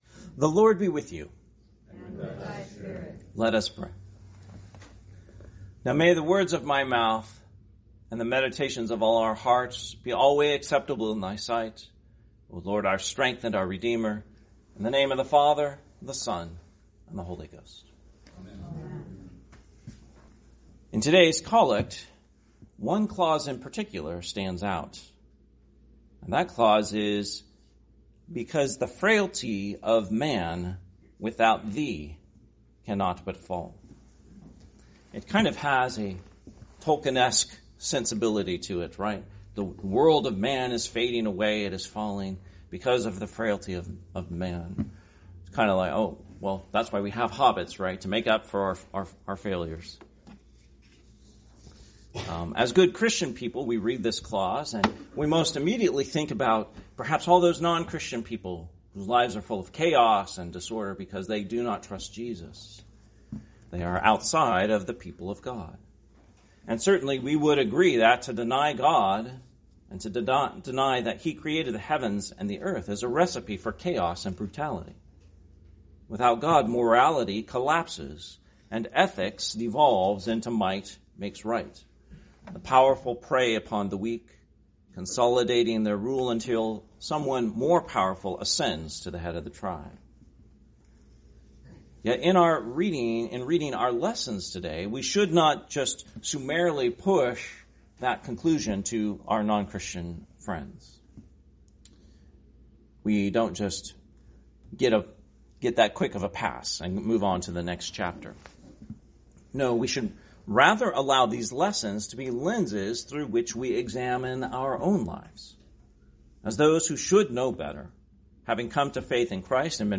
Sermon, 15th Sunday after Trinity, 2025 – Christ the King Anglican Church